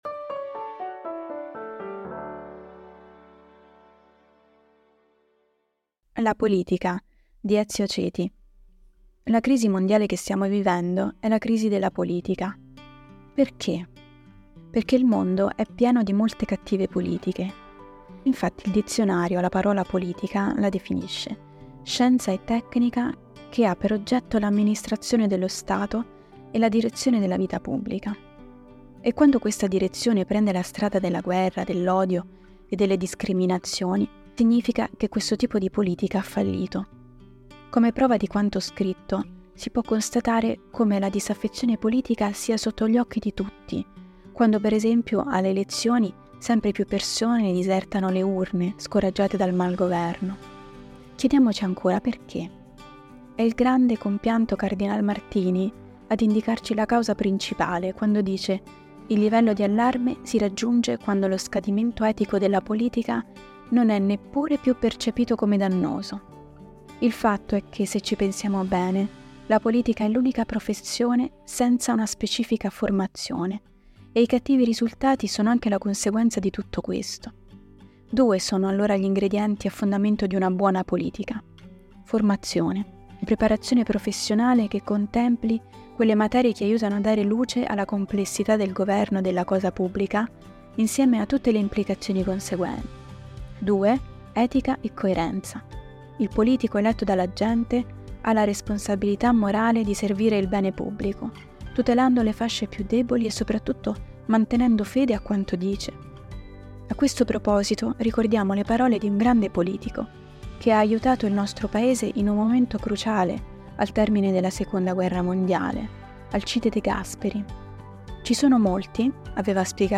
Per ogni numero, ci sarà una selezione di articoli letti dai nostri autori e collaboratori.
Al microfono, i nostri redattori e i nostri collaboratori.